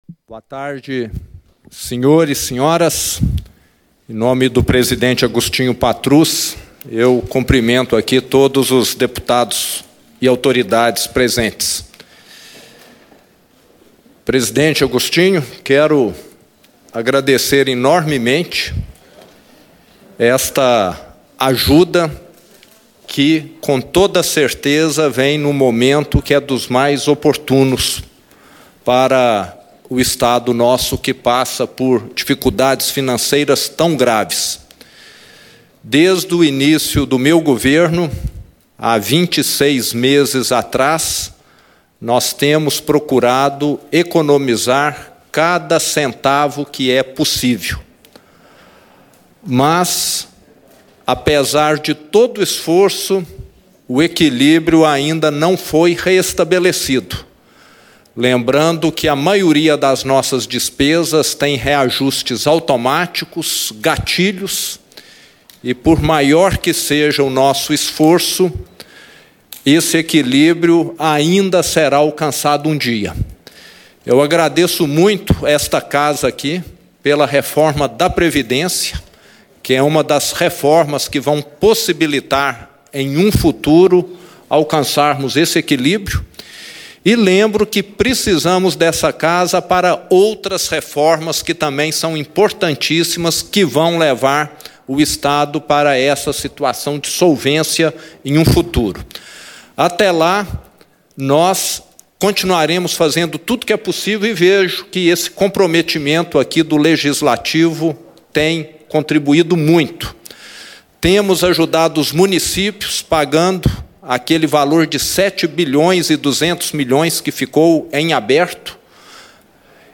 O pronunciamento foi feito durante solenidade de repasse de um cheque simbólico de R$80 milhões.
Íntegra - Governador Romeu Zema recebe repasse da ALMG e comenta o uso dos recursos
Discursos e Palestras